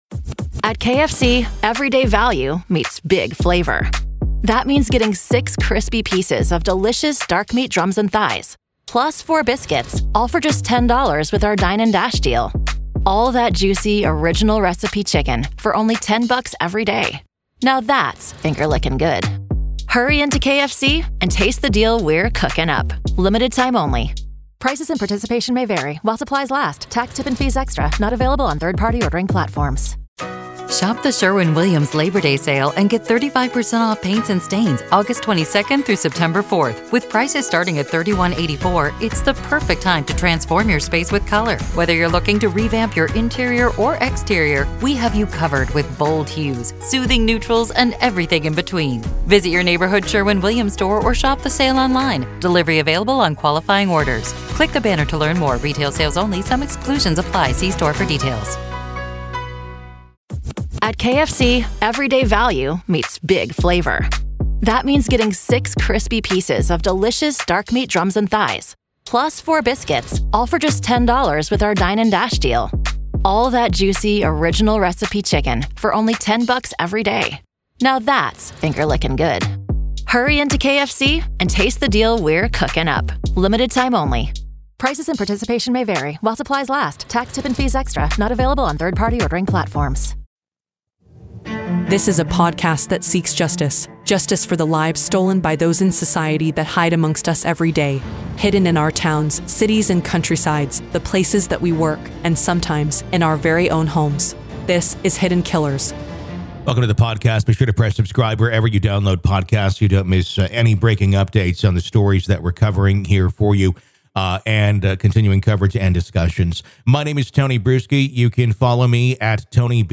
Today we're joined by Criminal Defense Attorney